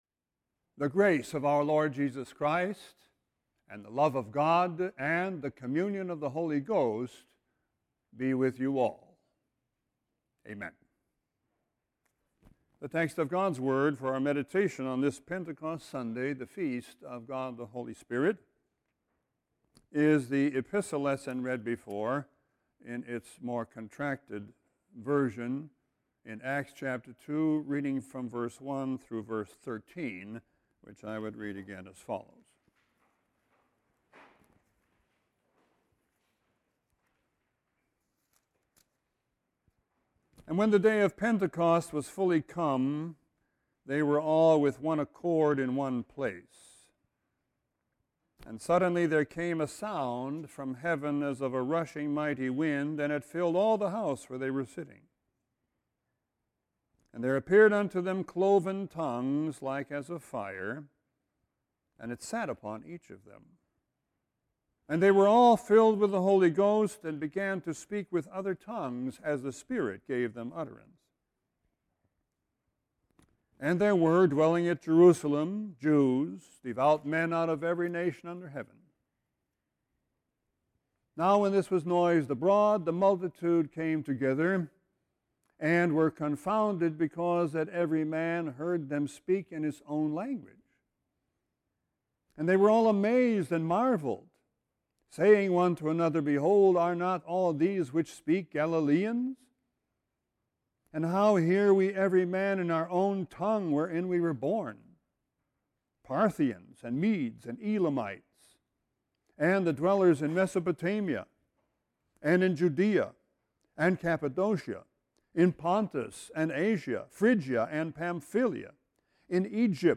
Sermon 6-8-14.mp3